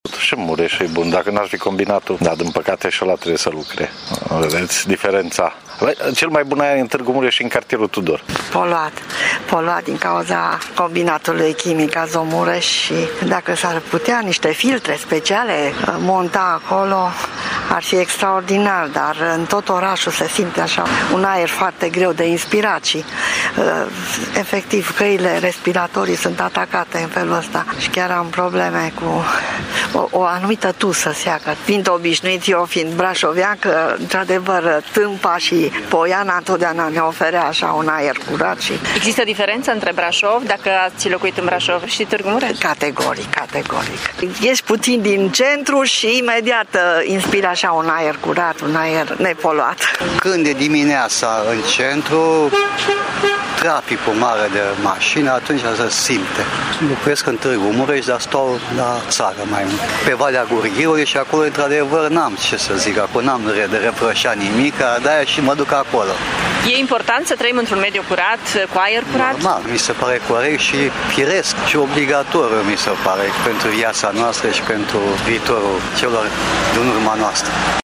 Locuitorii cred că emisiile de amoniac ne-au afectat de-a lungul timpului starea de sănătate: